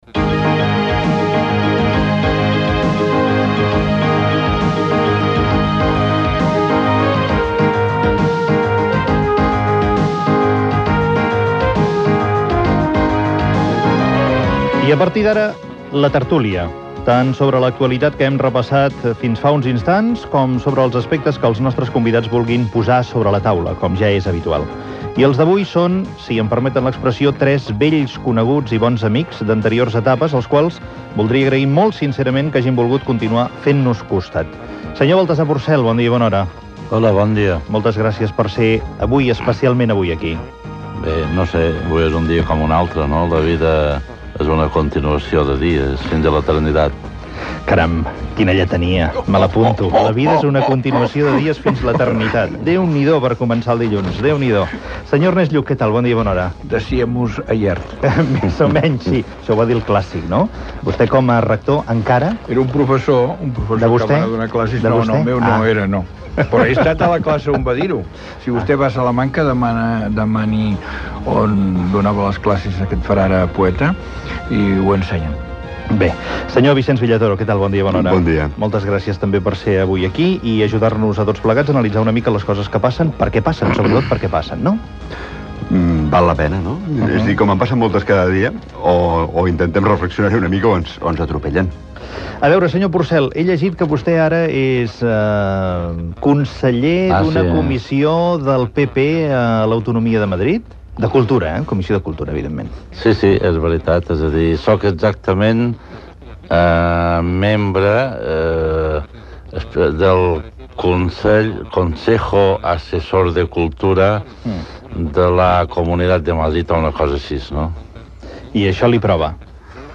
Fragment de la tertúlia amb Baltasar Porcel, Ernest Lluch i Vicenç Villatoro.
Info-entreteniment